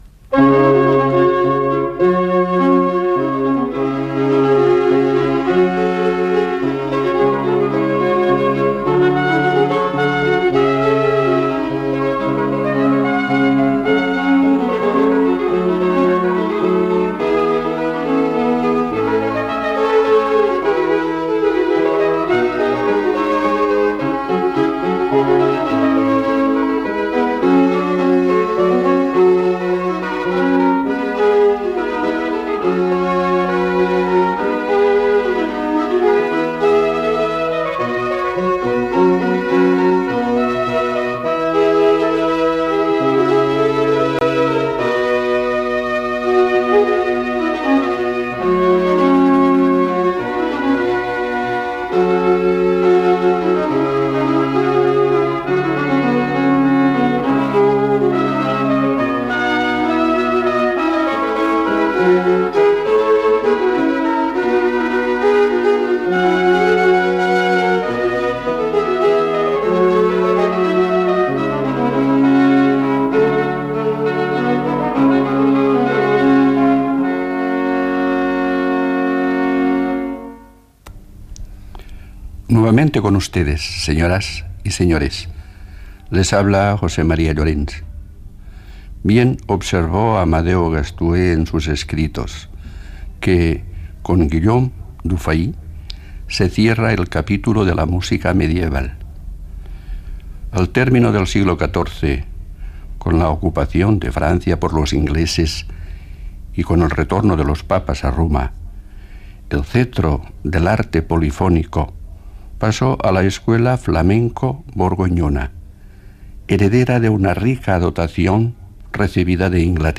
Sintonia, salutació, situació musical al segle XV a Europa i els compositors John Dunstable i Guillaume Dufay i tema musical
Musical